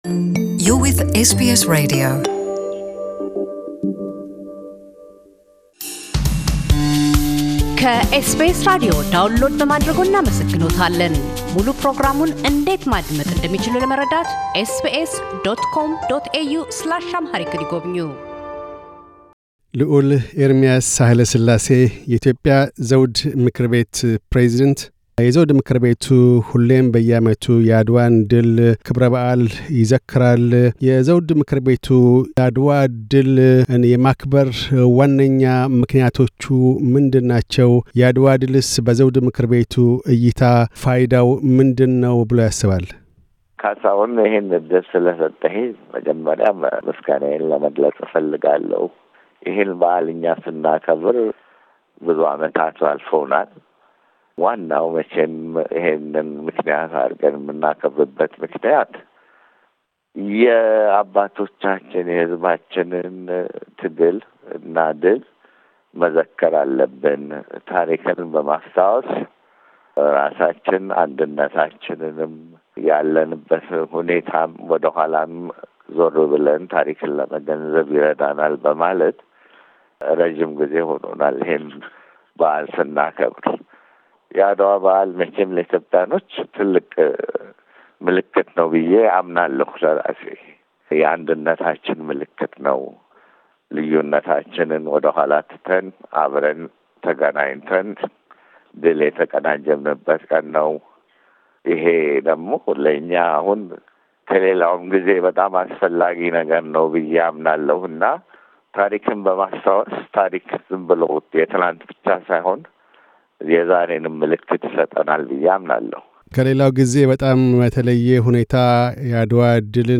ልዑል ኤርሚያስ ሣህለ ሥላሴ ፤ የኢትዮጵያ ዘውድ ምክር ቤት ፕሬዝደንት ፤ ስለ አድዋ ድል የአንድነት ምልክትነትና መጪውም ጊዜ ለኢትዮጵያውያን መልካም ዕድል ይዞ ሊመጣ እንደሚችል ያላቸውን ተስፋ ይገልጣሉ።